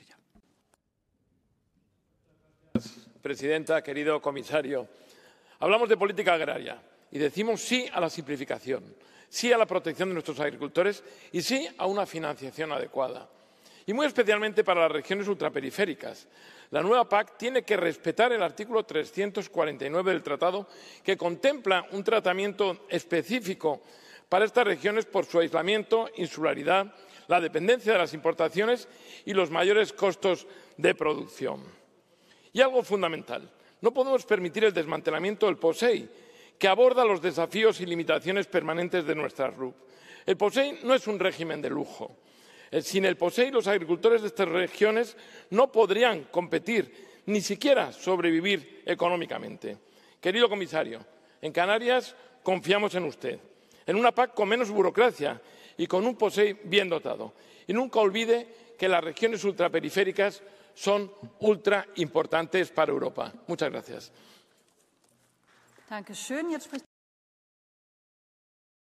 Gabriel Mato defiende en el Parlamento Europeo la importancia del POSEI para la agricultura en regiones ultraperiféricas como Canarias, resaltando su necesidad y eficacia ante la nueva PAC.
Intervencio--769-n-Gabriel-Mato.mp3